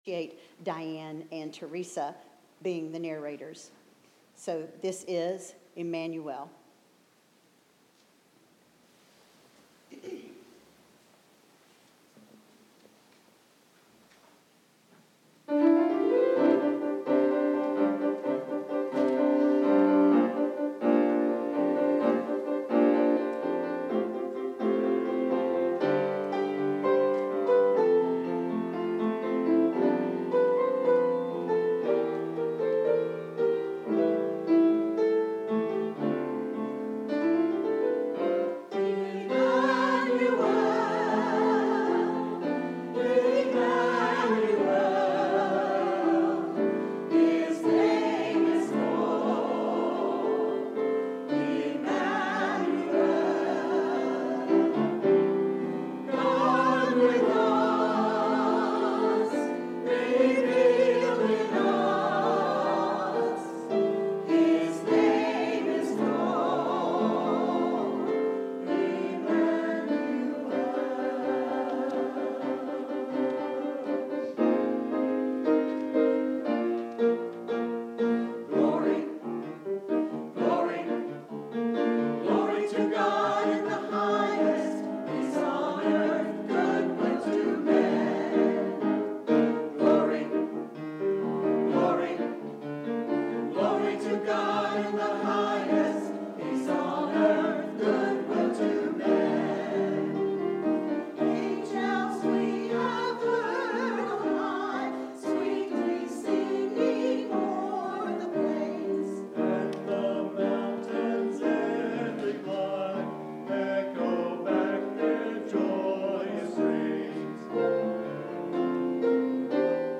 Emmanuel: Celebrating Heaven's Child - Christmas Cantata